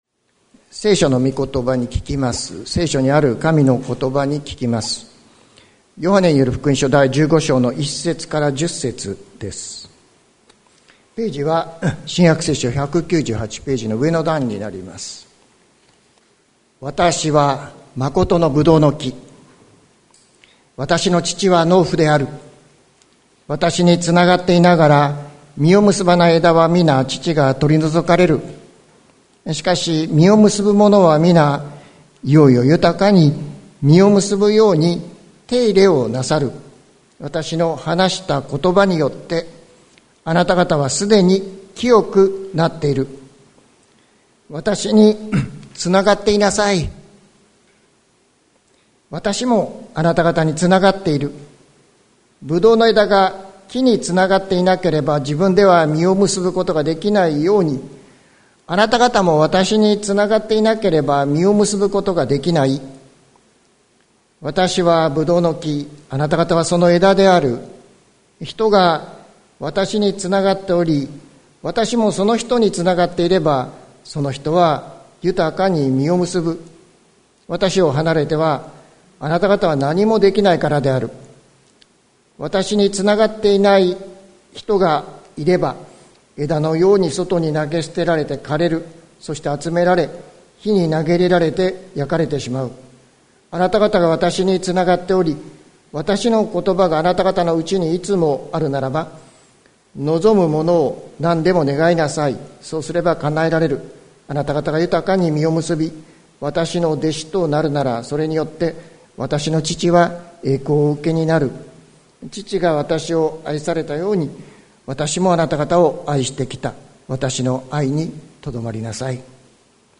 2023年07月18日朝の礼拝「大丈夫、ここでつながろう」関キリスト教会
説教アーカイブ。